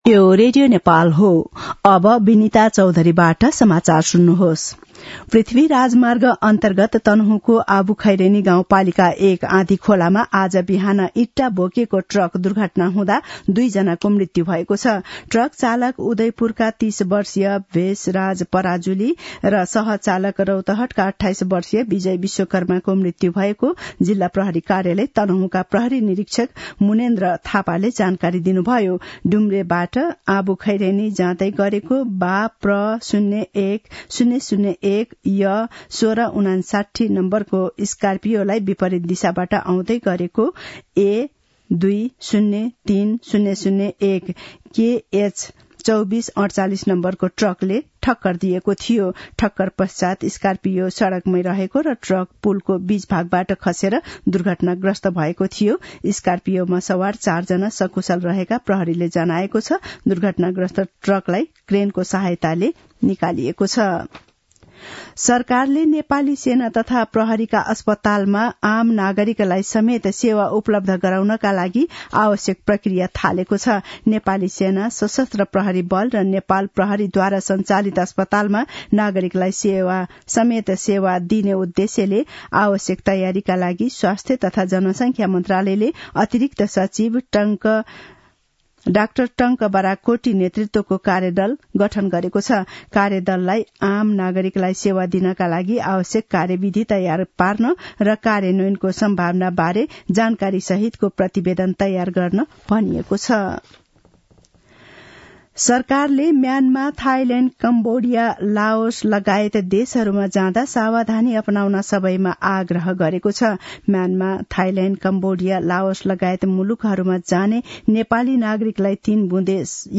दिउँसो १ बजेको नेपाली समाचार : १० पुष , २०८१
1-pm-nepali-news-1-18.mp3